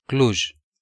Cluj County (Romanian pronunciation: [kluʒ]
Ro-Cluj.ogg.mp3